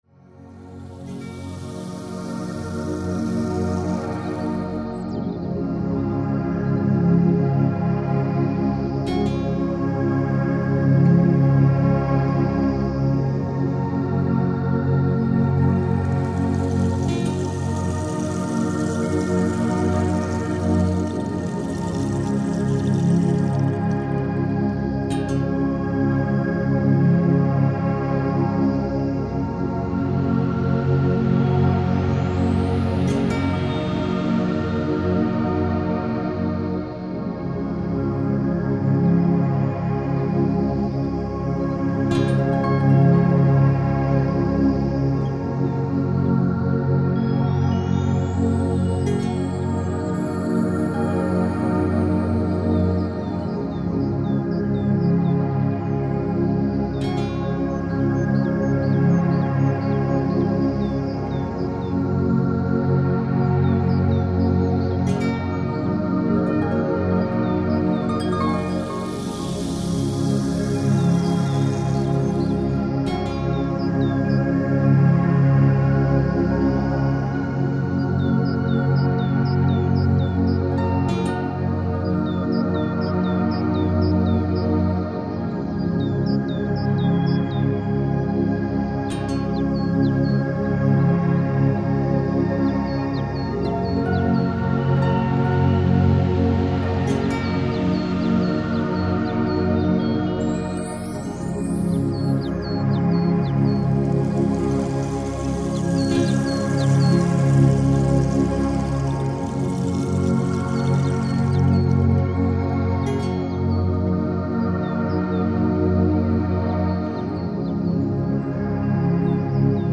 Reiner Klang   15:30 min
Für Fortgeschrittene liegt die Übung ohne textliche Anleitung nur mit reinen Geräuschen und Musik als abschließende Version vor.